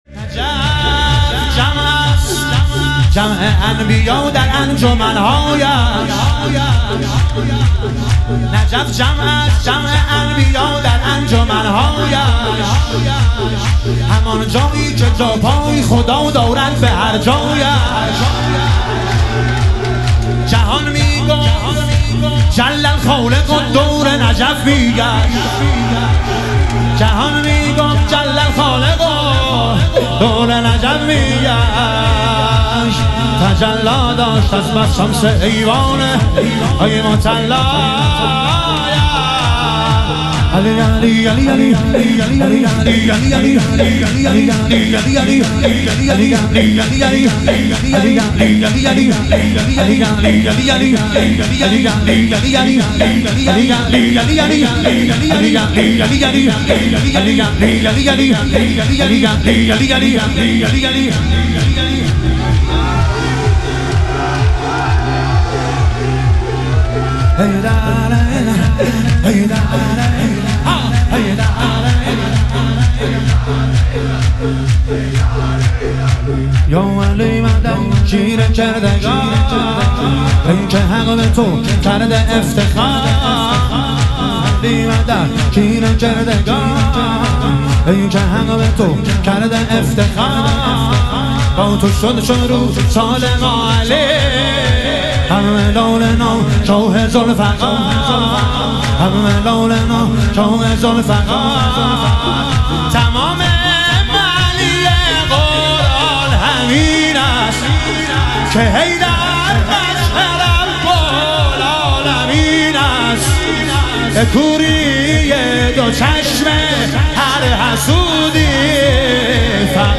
شهادت امام جواد علیه السلام - شور